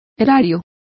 Complete with pronunciation of the translation of treasury.